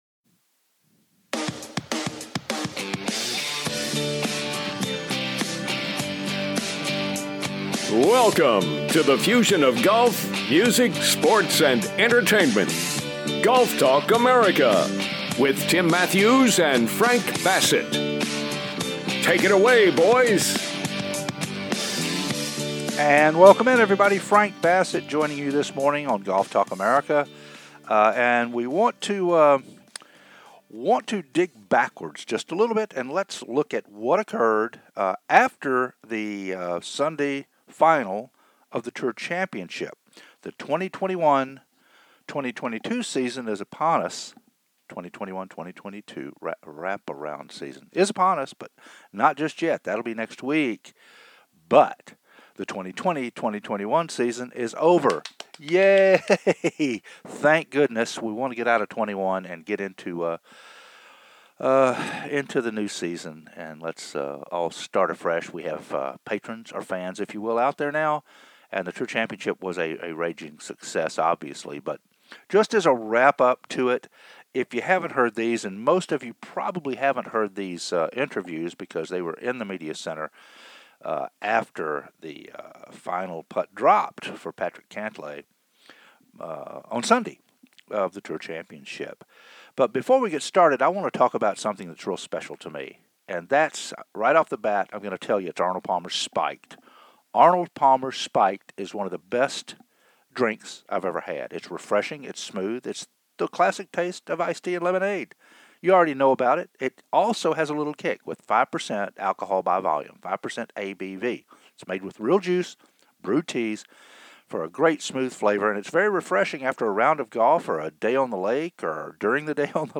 The Tour Championship media center interviews from East Lake Golf Club